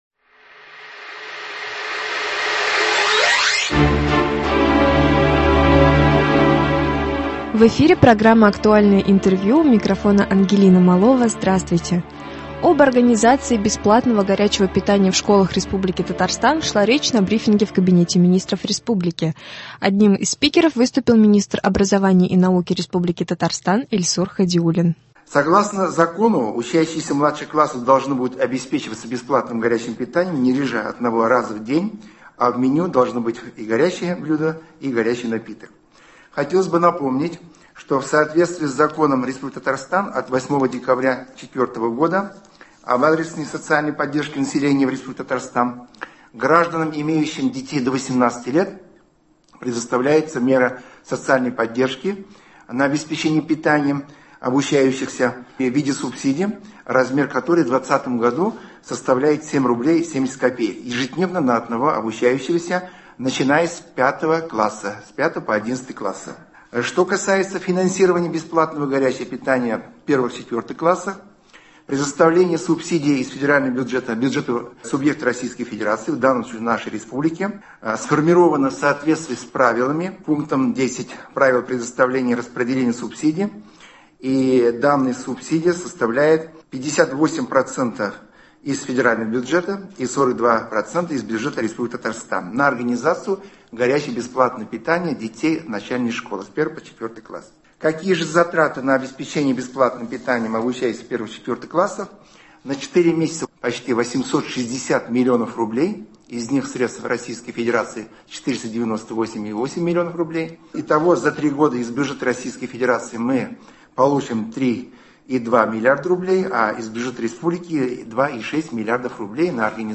Актуальное интервью (09.12.20)